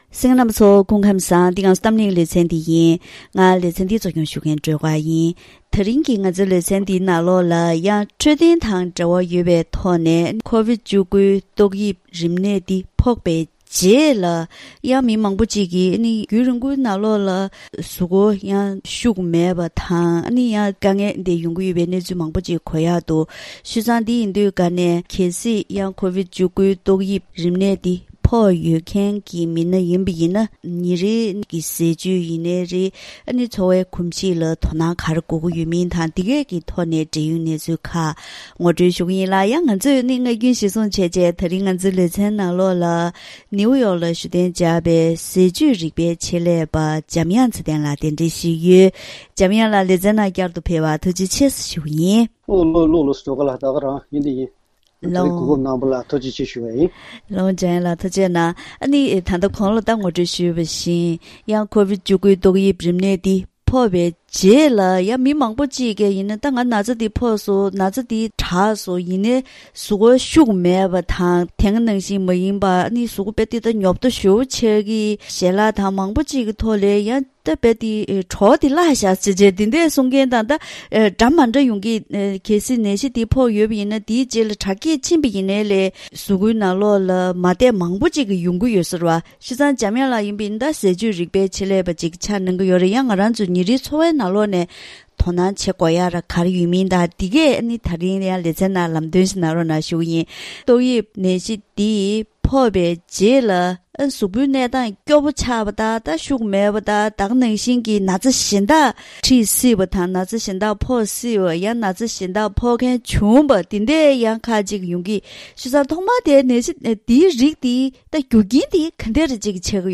༄༅།།དེ་རིང་གི་གཏམ་གླེང་ཞལ་པར་ལེ་ཚན་ནང་ཀོ་ཝཌི་༡༩ཏོག་དབྱིབས་རིམས་ནད་ཕོག་པའི་རྗེས་རྒྱུན་རིང་ཞིག་ནང་ལུས་པོ་གསོ་མི་ཐུབ་པའི་དཀའ་ངལ་དང་།